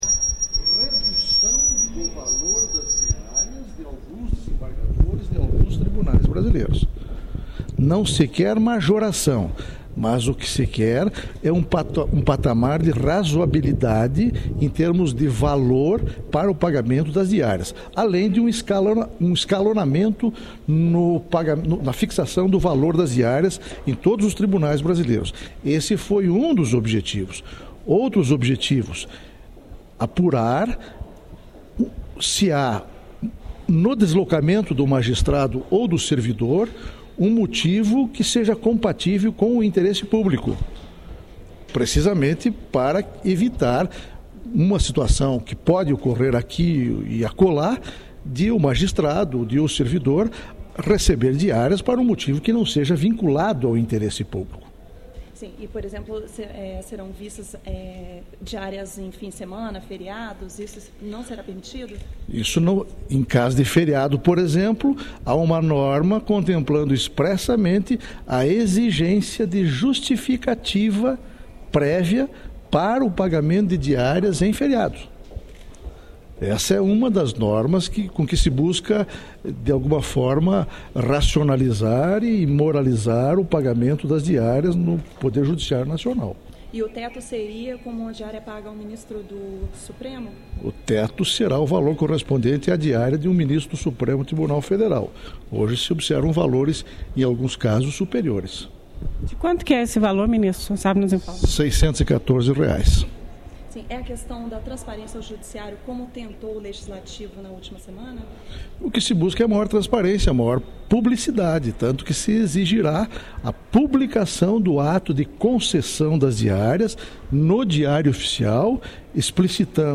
aqui a entrevista coletiva concedida pelo ministro João Oreste Dalazen.